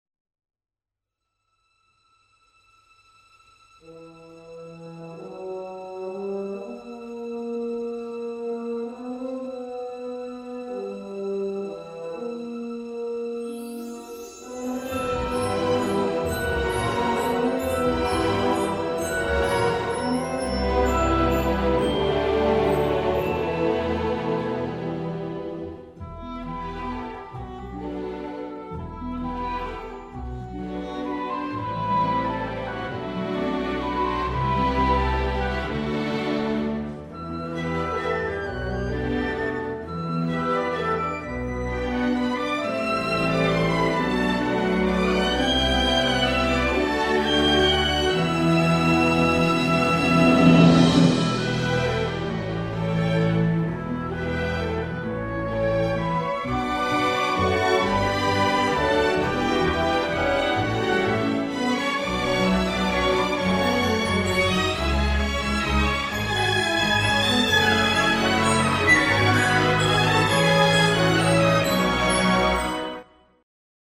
• GRAND ORCHESTRAL SCORE IN THE TRADITION OF JOHN WILLIAMS